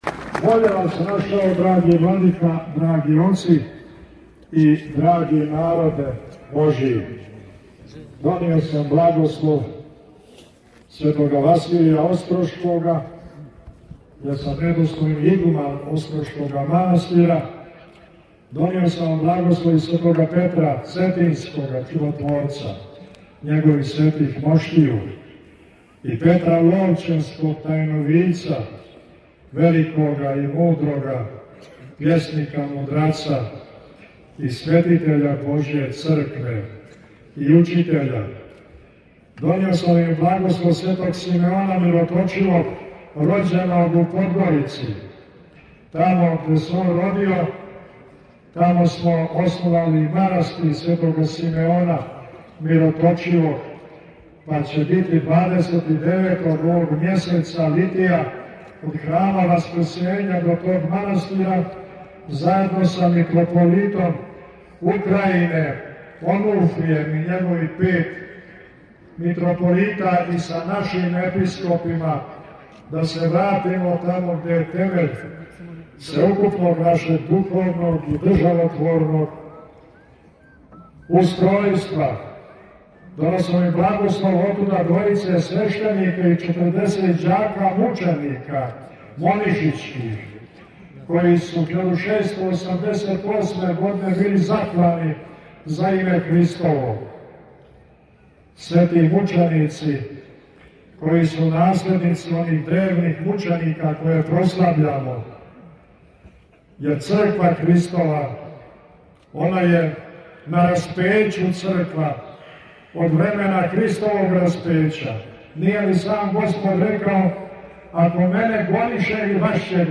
Затим је предвођена Архијерејима улицама Пљеваља кренула величанствена колона верног народа, њих око 20000 уз певање тропара и молитава као и повремено узвикивање познатог поклича НЕ ДАМО СВЕТИЊЕ.
Литија се, као и до сада, зауставила на тргу испред хотела Пљевља где се присутнима најпре обратио Епископ Атанасије који је поздравио уваженог госта вечерашњег скупа, а затим и Митрополит Амфилохије који је пренео благослове Светог Василија Острошког: – Донио сам благослов Светога Василија Острошкога јер сам недостојни игуман острошкога манастира, донио сам вам благослов и Светога Петра Цетињскога Чудотворца, његових светих моштију, и Петра Ловћенског Тајновидца, великога и мудрога пјесника мудраца и светитеља Божије Цркве и учитеља, донио сам вам и благослов Светог Симеона Мироточивог, рођен